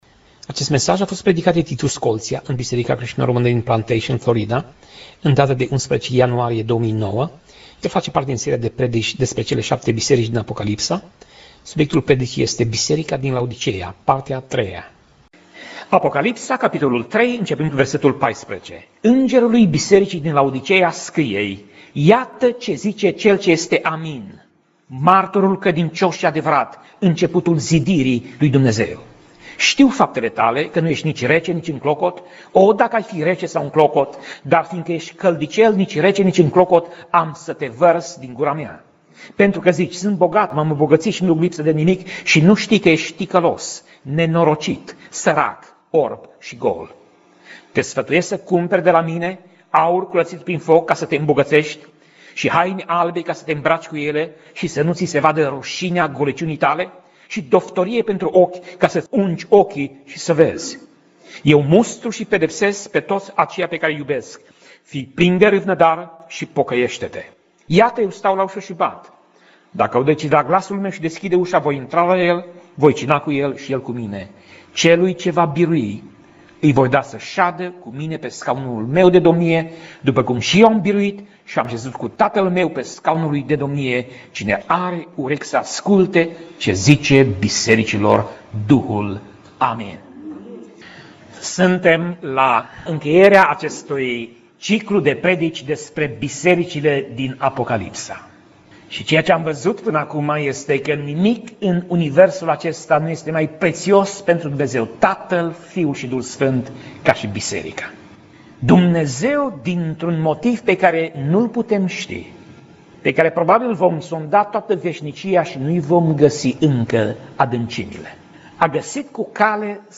Pasaj Biblie: Apocalipsa 3:14 - Apocalipsa 3:22 Tip Mesaj: Predica